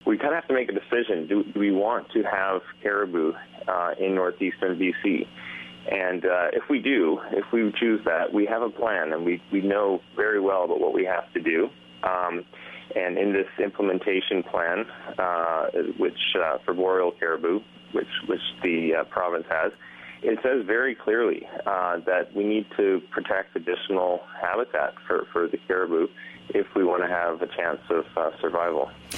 We've left you with a clip from an early morning interview from the show.